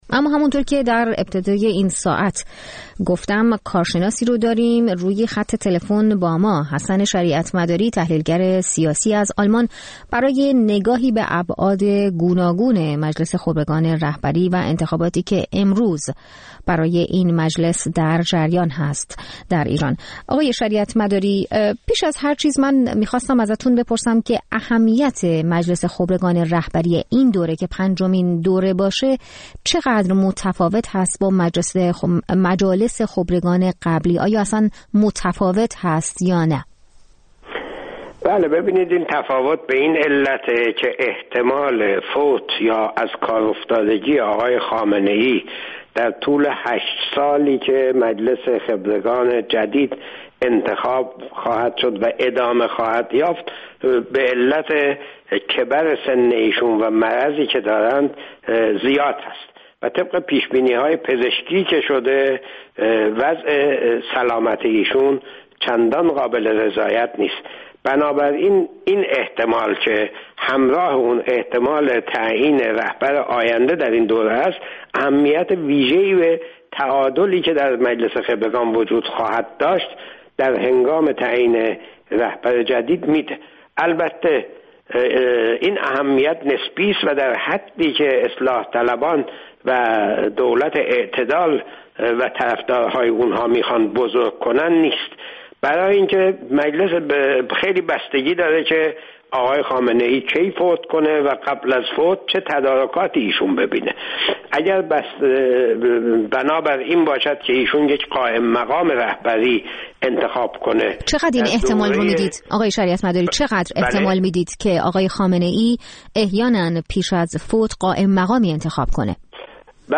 گفت‌وگوی
فعال سیاسی، درباره انتخابات هفتم اسفند